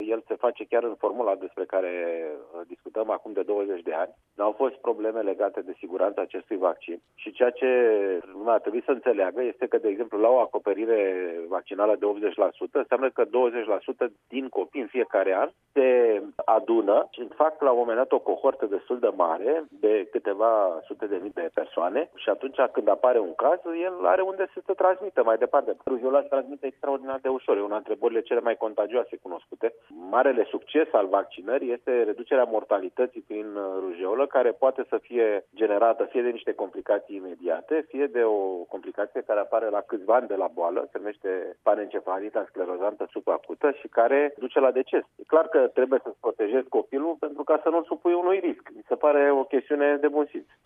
Invitat la RRA, ministrul Sănătăţii, Alexandru Rafila, a îndemnat părinţii să îşi imunizeze copiii, precizând că vaccinul împotriva rujeolei este sigur: